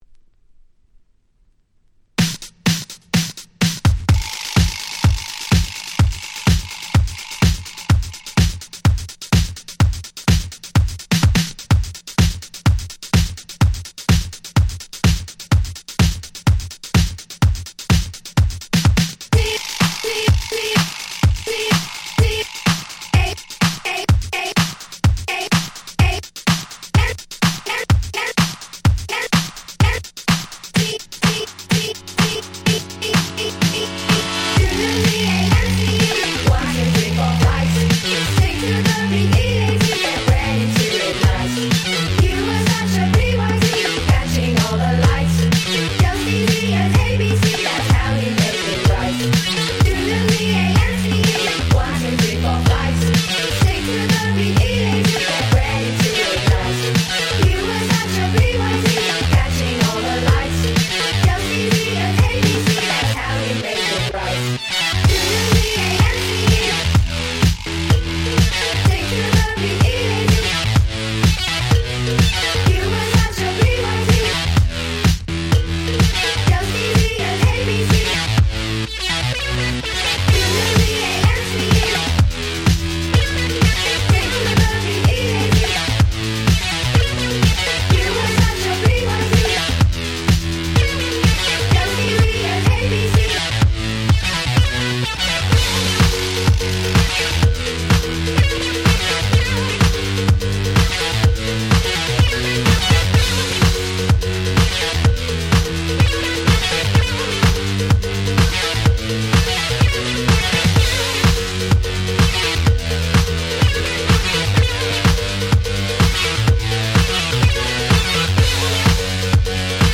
07' Super Hit Elctoronic Disco !!
フレンチ エレクトロニックディスコ ダンスポップ